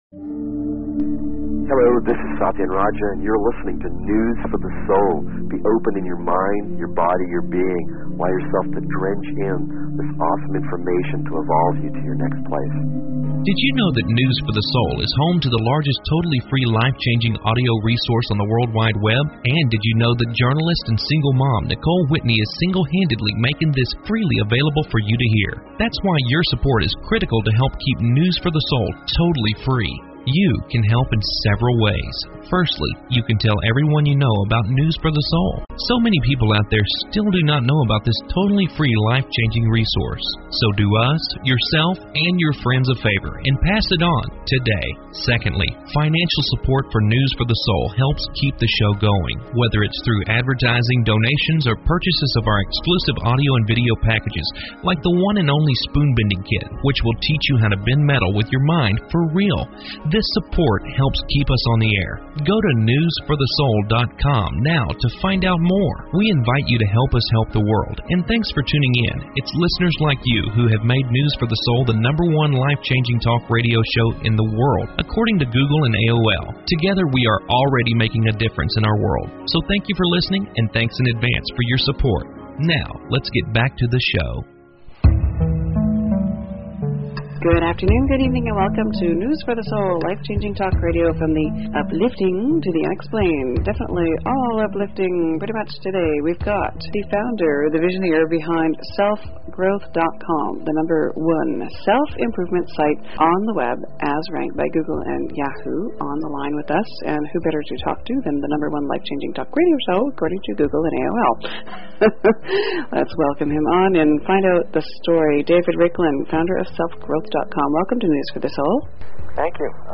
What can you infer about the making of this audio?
Back by popular demand: LIVE ON AIR GROUP HEALINGS!